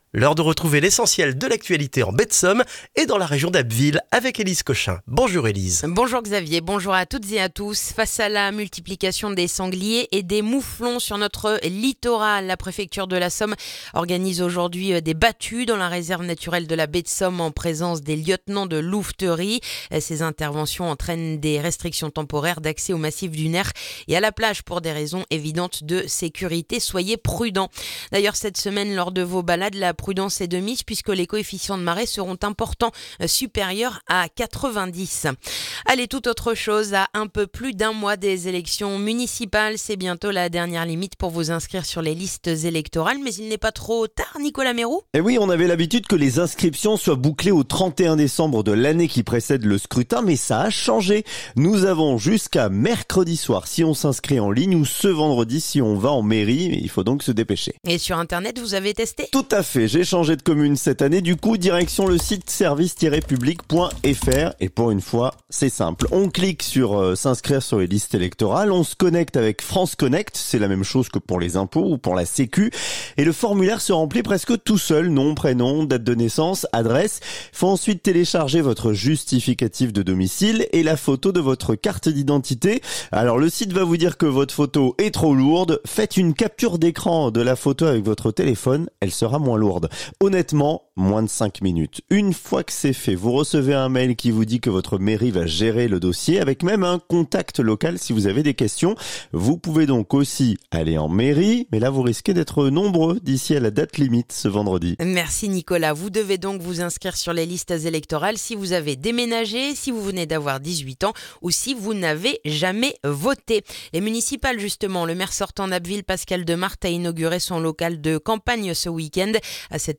Le journal du lundi 2 février en Baie de Somme et dans la région d'Abbeville